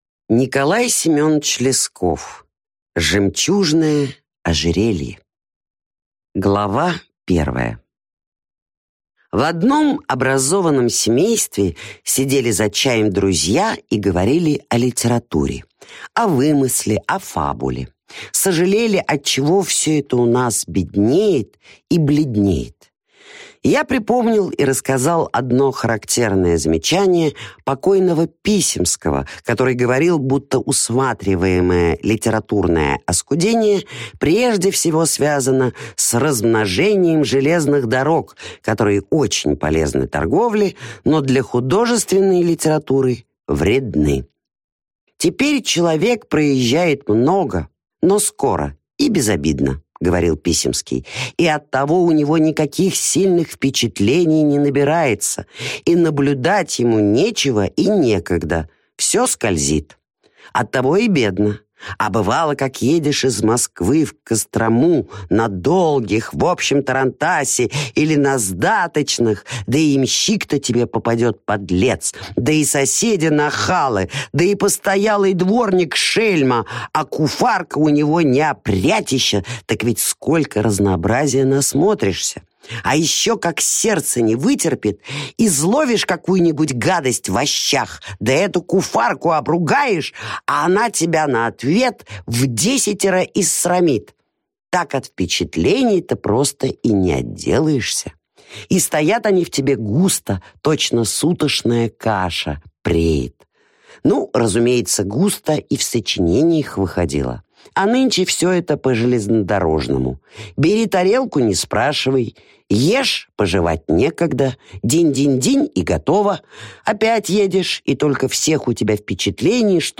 Аудиокнига Рождественские рассказы | Библиотека аудиокниг